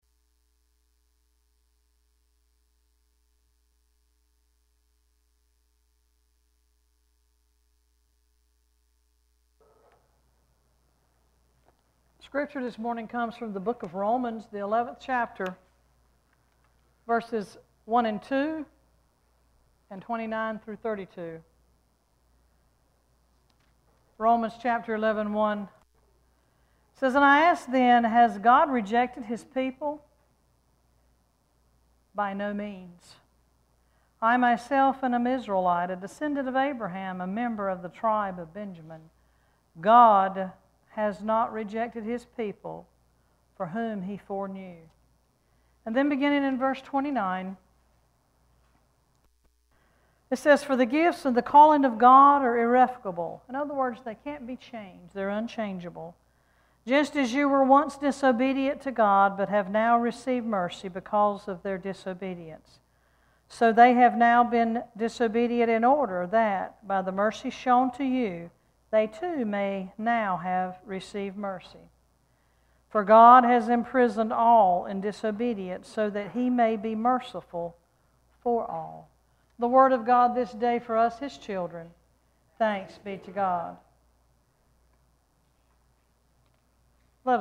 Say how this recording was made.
Worship Service 8-20-17: “Never Give Up”